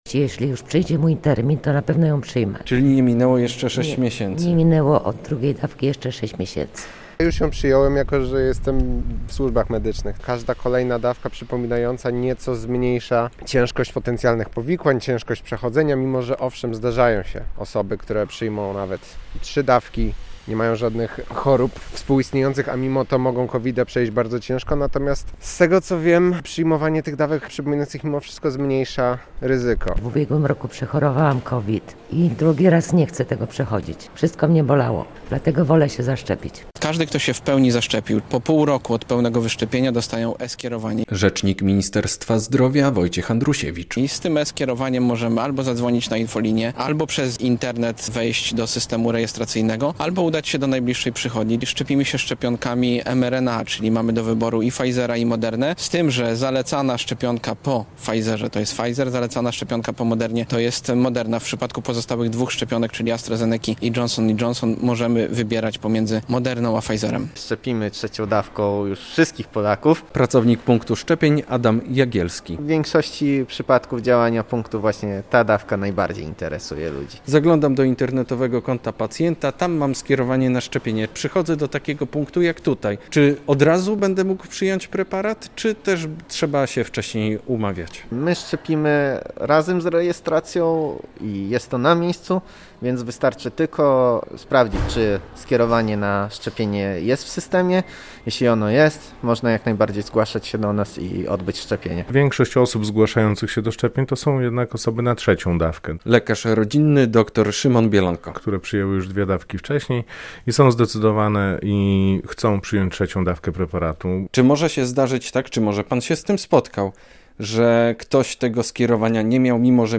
Szczepienia trzecią dawką przeciw COVID-19 - relacja